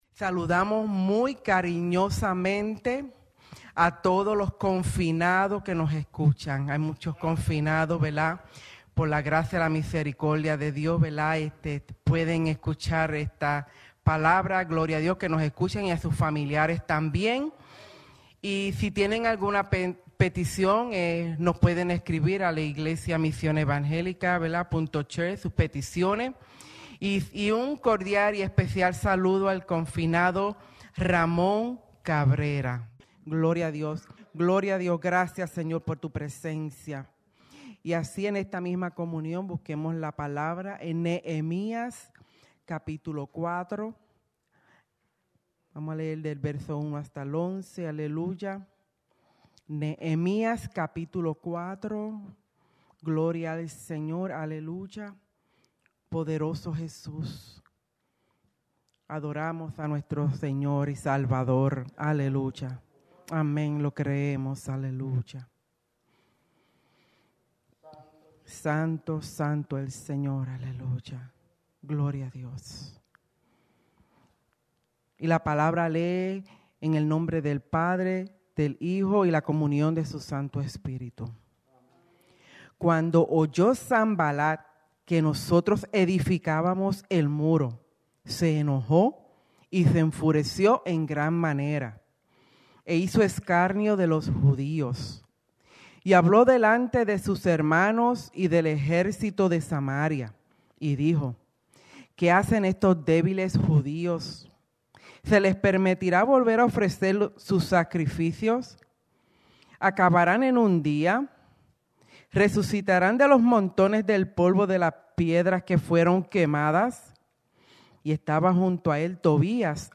Predica
Souderton, PA